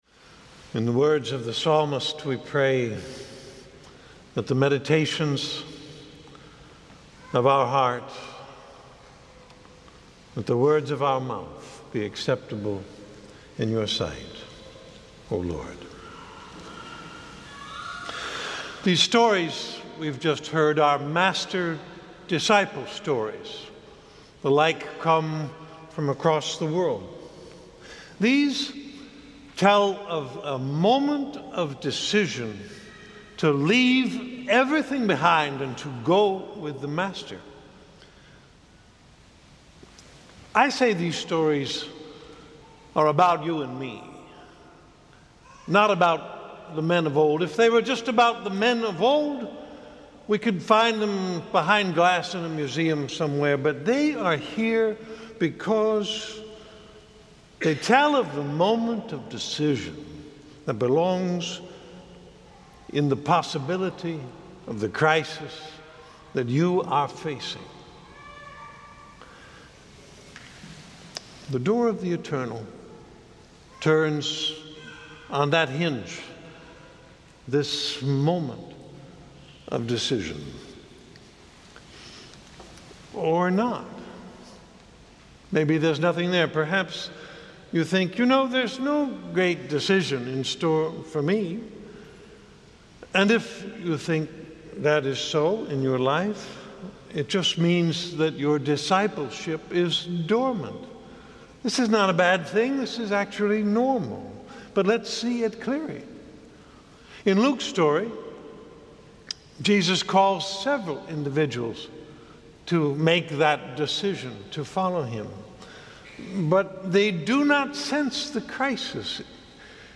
Download PDF (This is the first sermon in the series Learner’s Mind, which interprets the Elisha cycle as a guide in spiritual formation)